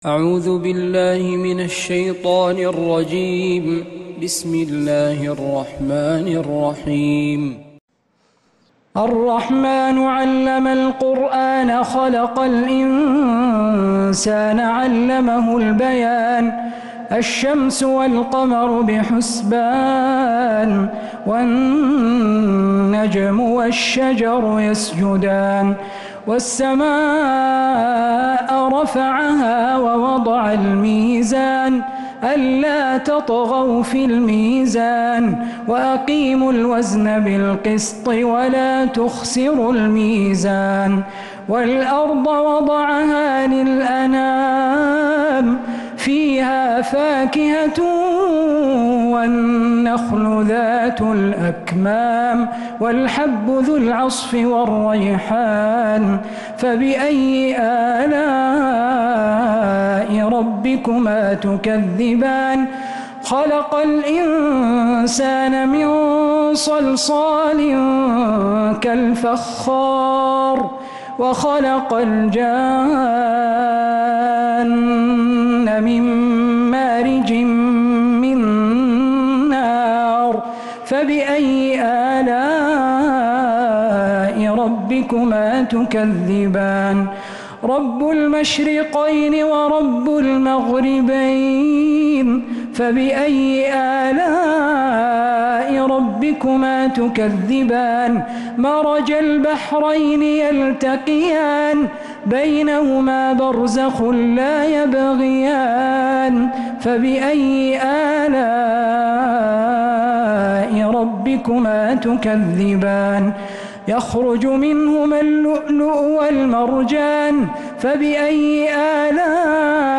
سورة الرحمن من تراويح الحرم النبوي للشيخ عبدالله القرافي | رمضان 1445 هـ > السور المكتملة للشيخ عبدالله القرافي من الحرم النبوي 🕌 > السور المكتملة 🕌 > المزيد - تلاوات الحرمين